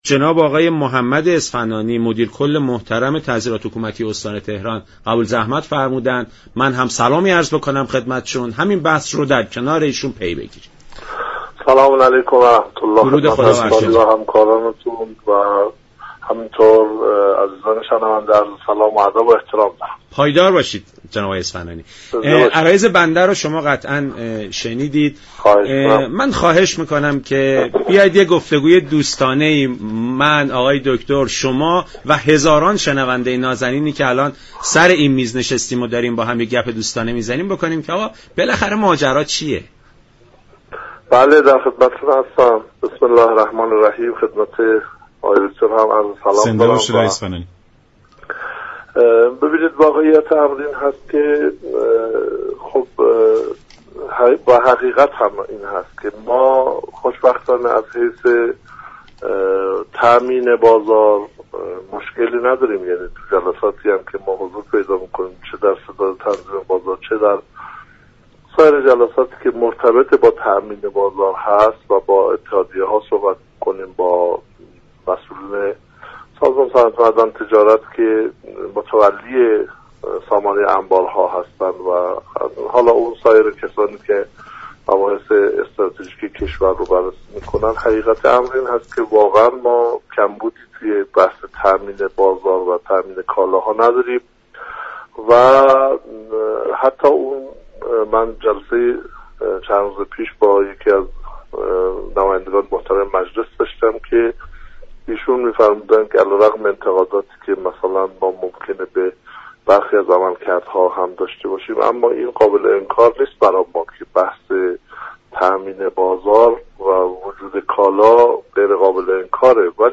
مدیر كل تعزیرات حكومتی استان تهران در برنامه سلام صبح بخیر رادیو ایران گفت:وقتی نظارت ها دقیق تر می شود و متخلفان و سودجویان كنار می روند، بازار ثبات بیشتری به خود می گیرد.